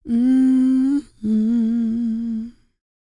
E-CROON P310.wav